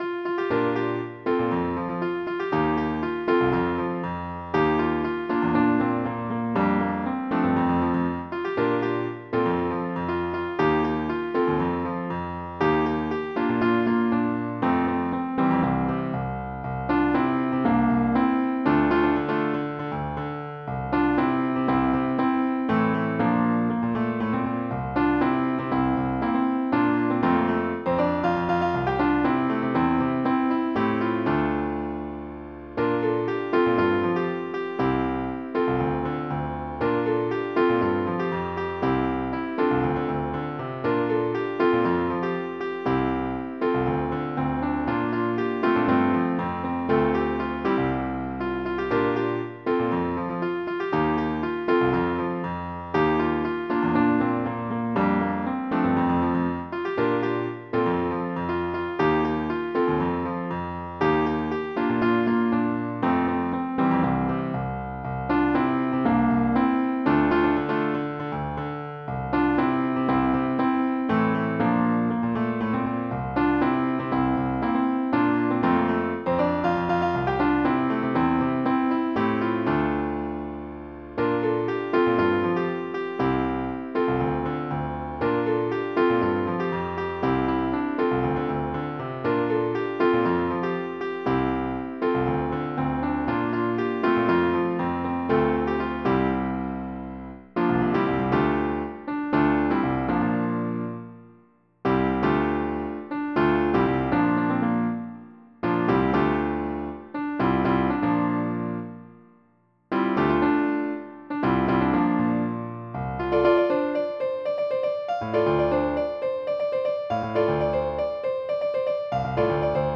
MIDI Music File
General MIDI